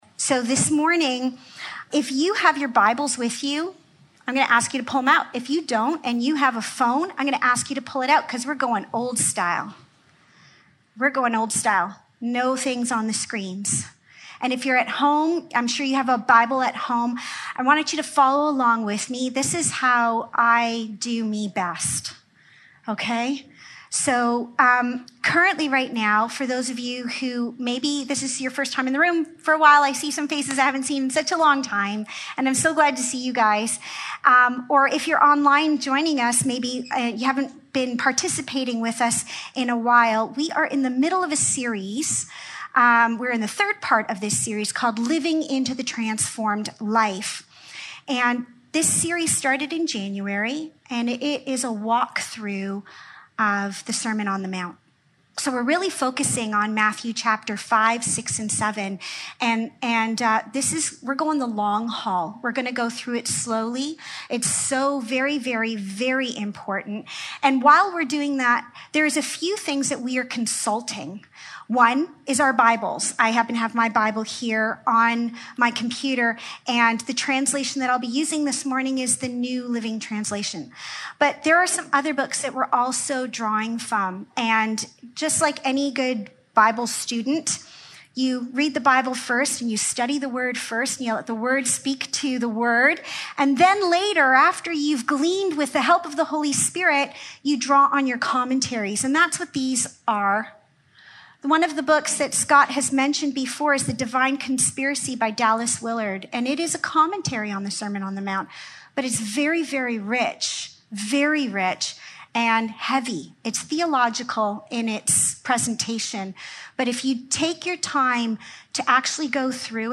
Matthew 5:38-42 Service Type: Sunday Morning Living into the Transformed Life Part 3